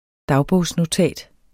Udtale [ ˈdɑwbɔˀws- ]